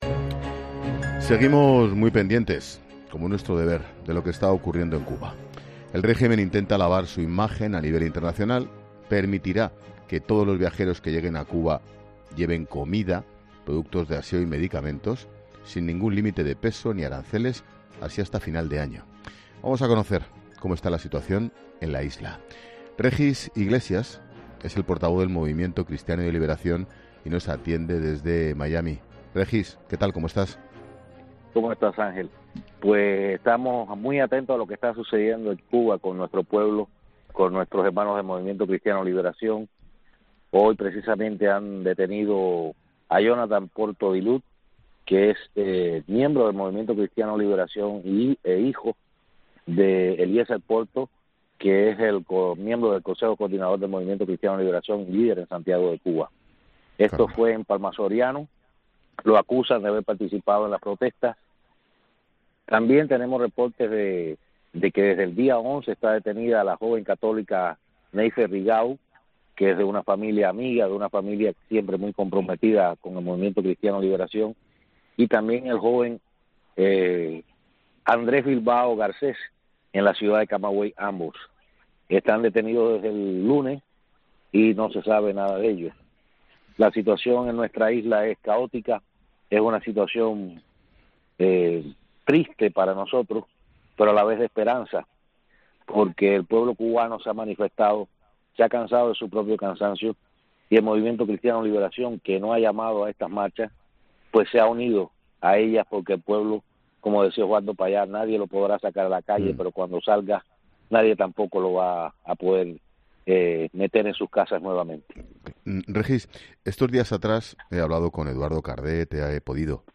Por otra parte, en la entrevista también se repasan los motivos por los cuales, está ocurriendo ahora este levantamiento del pueblo cubano.